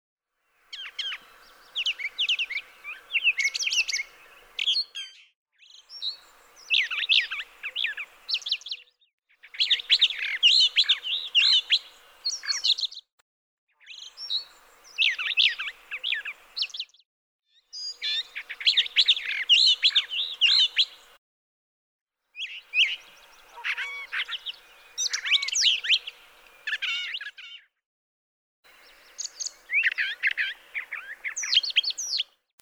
Brown thrasher
♫236. Seven excerpted song matches from ♫237, separated by fades. Note that the bird in the right track always follows the bird in the left track.
236_Brown_Thrasher.mp3